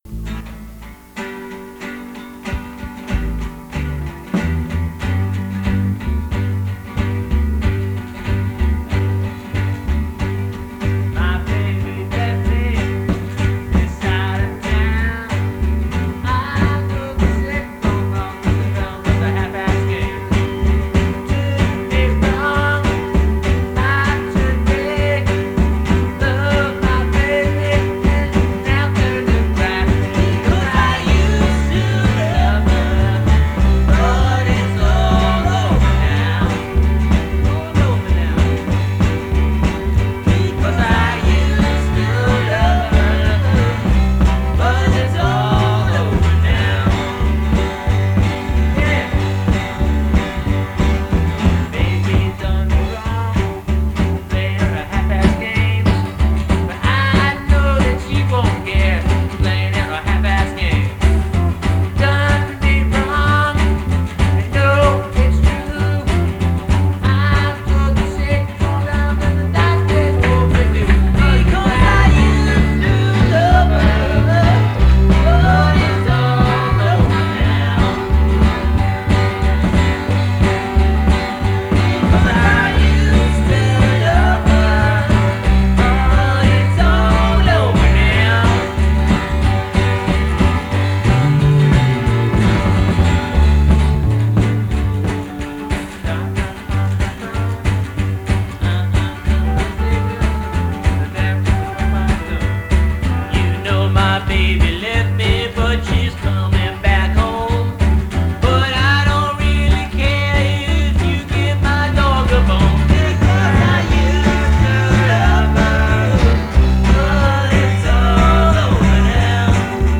their last ever jam session.